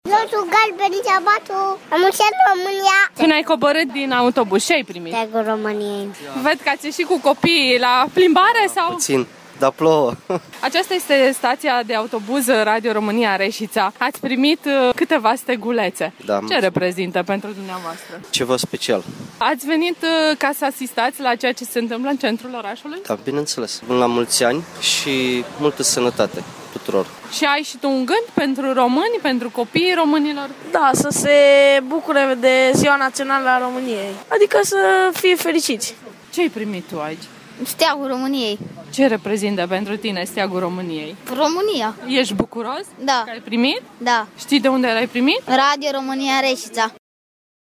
Reporterii s-au mai aflat astăzi, în centrul Reșiței, unde au împărțit alte sute de stegulețe, fluturate cu entuziasm de cei sosiți pentru a sărbători Ziua Națională, în ciuda timpului nefavorabil.
vox-stegulete-1-dec.mp3